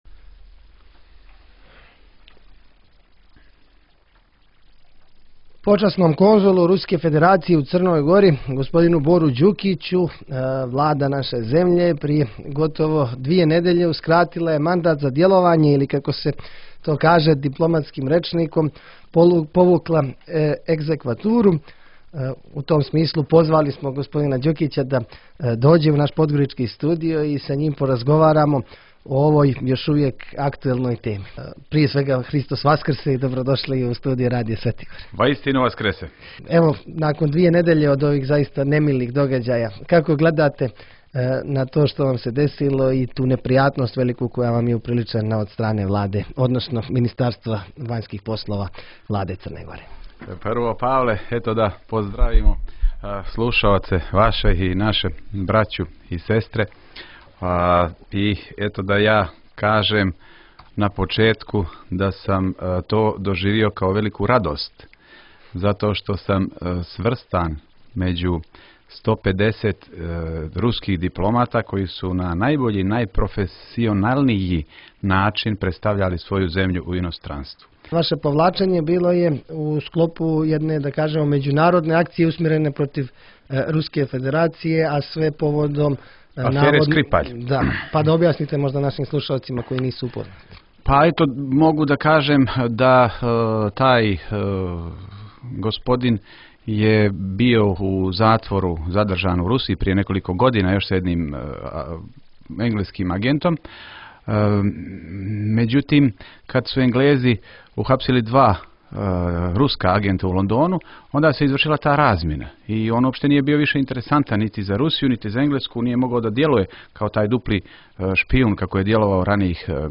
To je био повод за његово гостовање у студију радија Светигора.